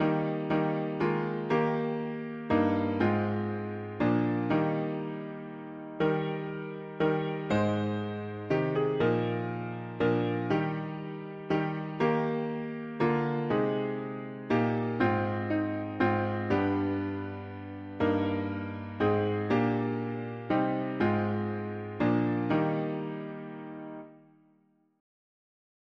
Key: E-flat major Meter: 77.77